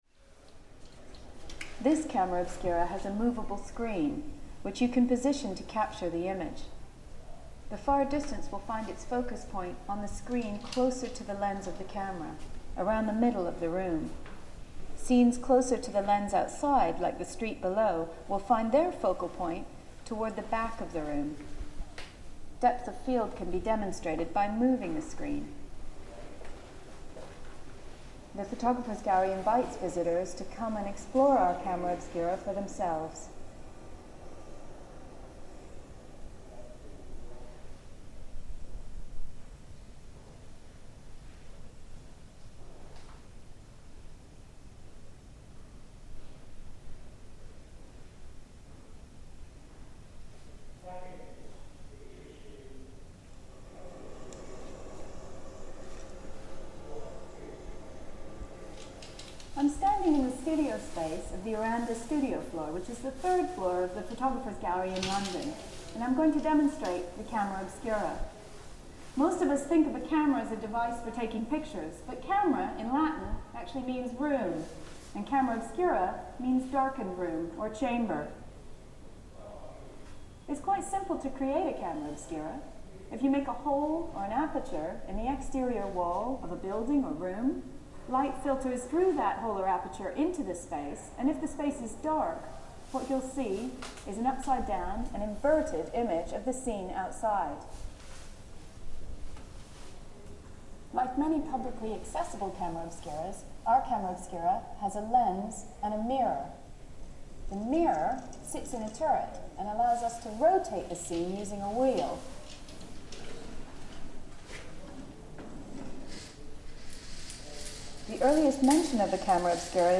In the Photographers' Gallery in London, there is a working camera obscura set up in a darkened space, through which you can rotate around a 360 degree view of the streets outside.
In this recording you can hear a video talking about how camera obscuras work, and the sound of the camera itself being rotated to throw different aspects of the view outside onto the screen.